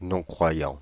Ääntäminen
Synonyymit athée Ääntäminen France (Île-de-France): IPA: /nɔ̃.kʁwa.jɑ̃/ Haettu sana löytyi näillä lähdekielillä: ranska Käännöksiä ei löytynyt valitulle kohdekielelle.